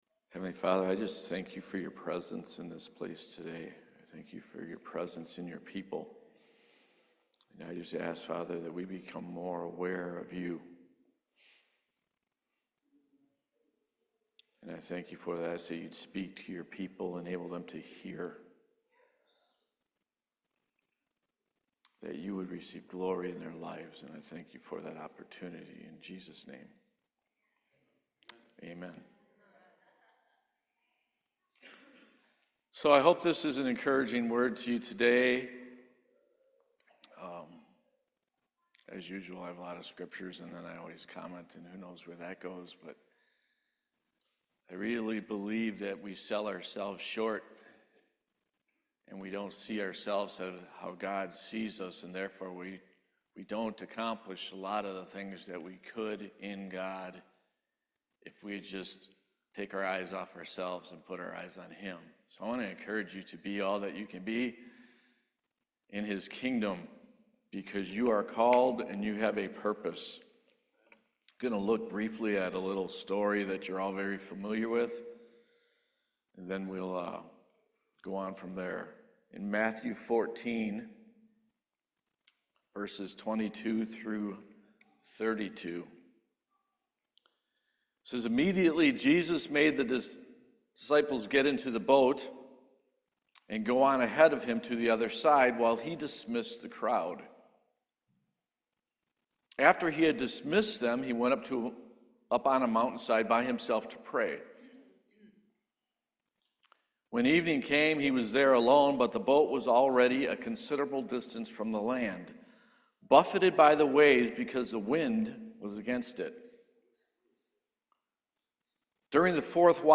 Service Type: Main Service